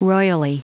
Prononciation du mot royally en anglais (fichier audio)
Prononciation du mot : royally